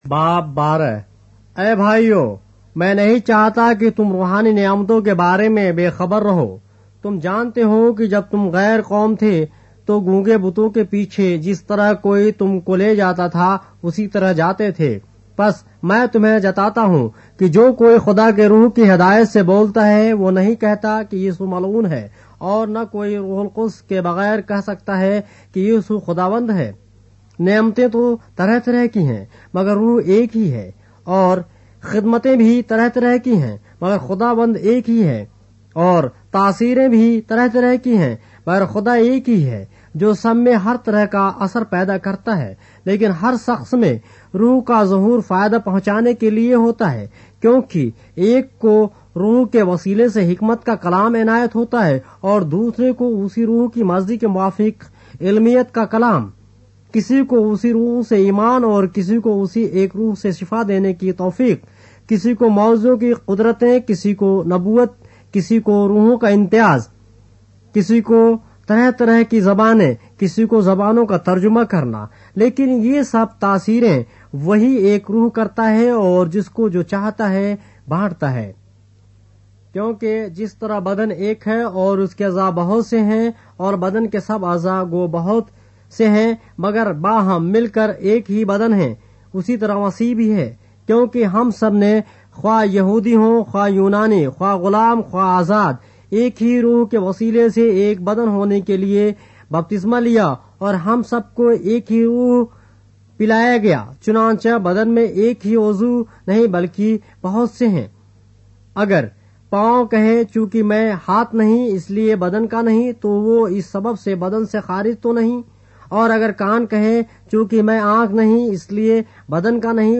اردو بائبل کے باب - آڈیو روایت کے ساتھ - 1 Corinthians, chapter 12 of the Holy Bible in Urdu